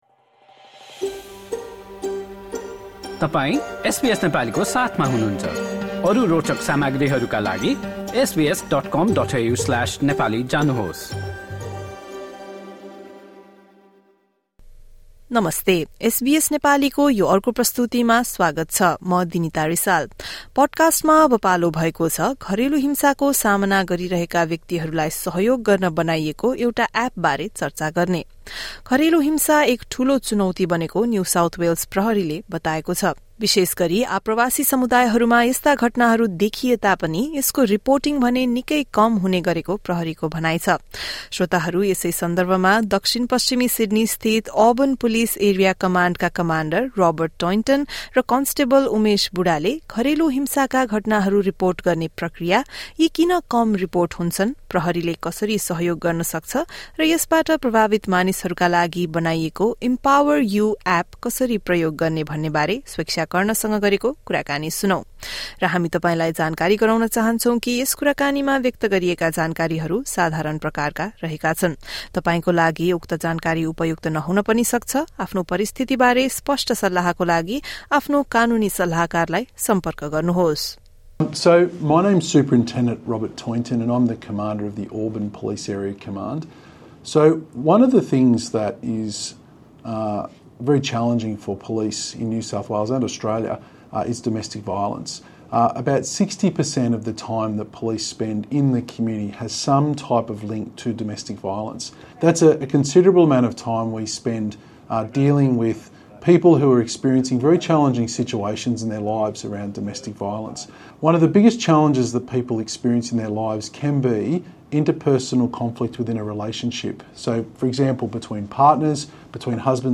र यसबाट प्रभावित मानिसहरूका लागि बनाइएको ‘इम्पावर यु’ एप कसरी प्रयोग गर्ने भन्नेबारे एसबिएस नेपालीसँग गरेको कुराकानी सुन्नुहोस्।